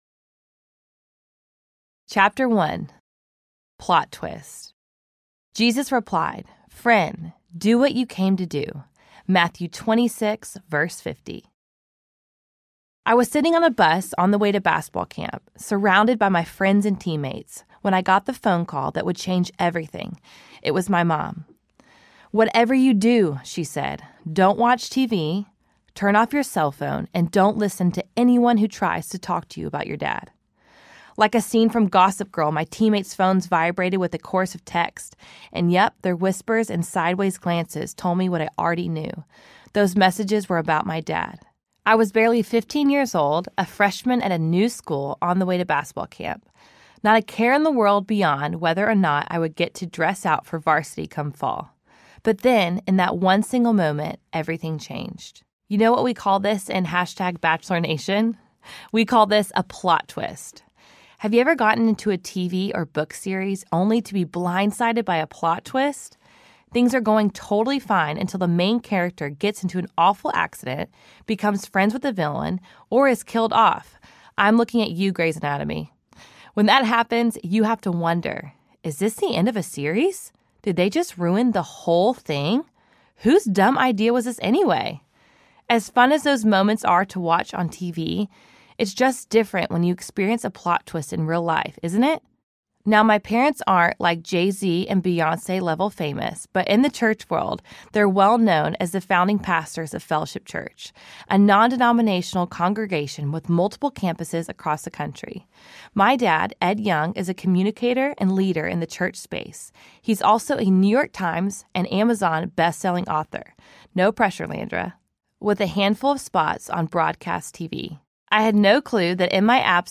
A Different Kind of Love Story Audiobook
Narrator
4.0 Hrs. – Unabridged